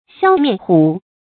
笑面虎 xiào miàn hǔ 成语解释 比喻外表善良，心地凶狠的人。